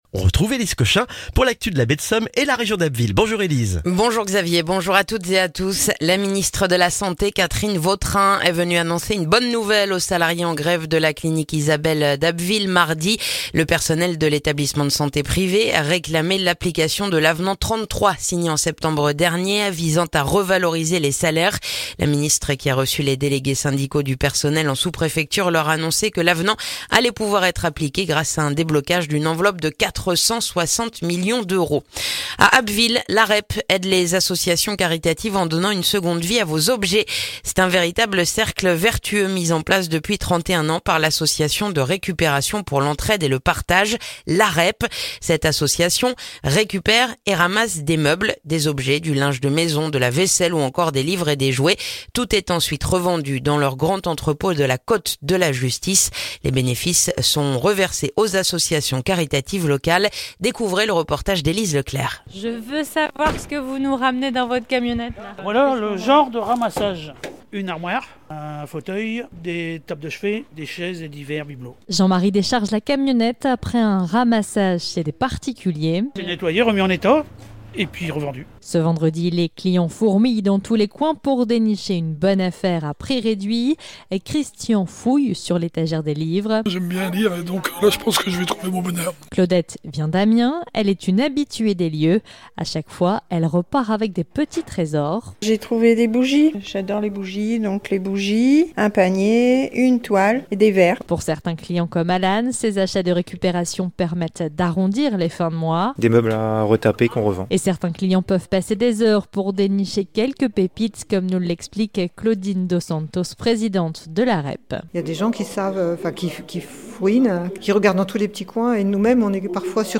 Le journal du jeudi 20 juin en Baie de Somme et dans la région d'Abbeville